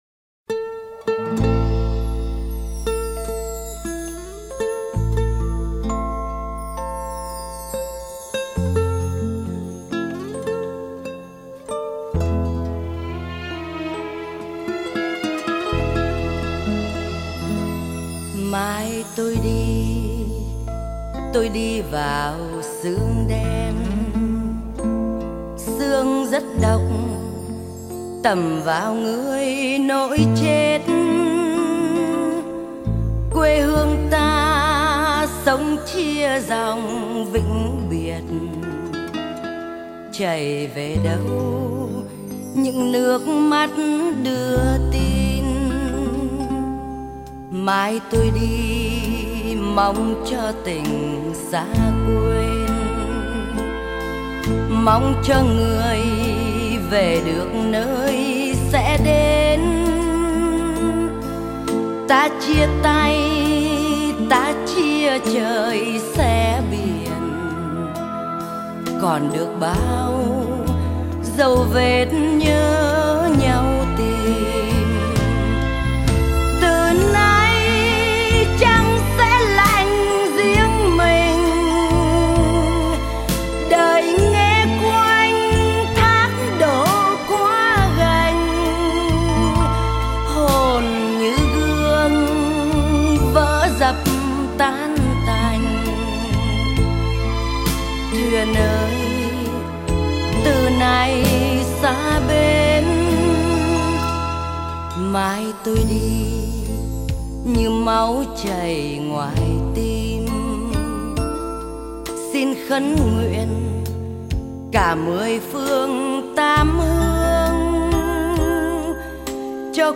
tiếng hát